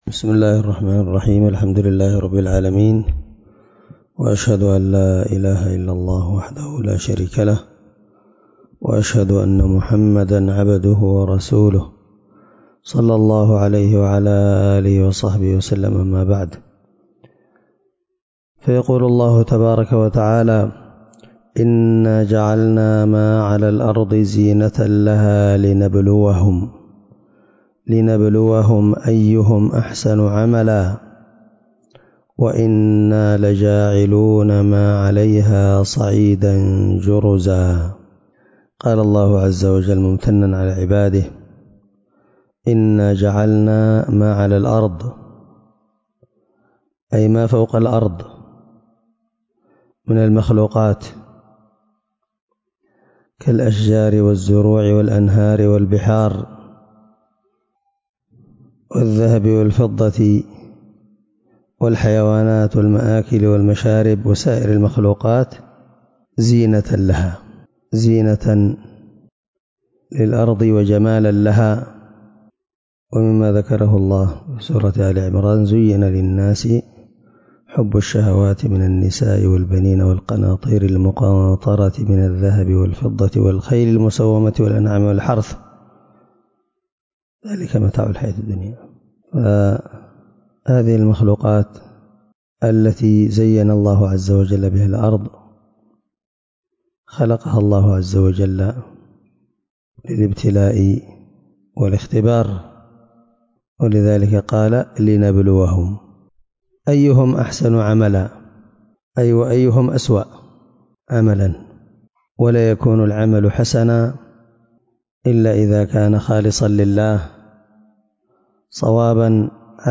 الدرس2 تفسير آية (7-8) من سورة الكهف
18سورة الكهف مع قراءة لتفسير السعدي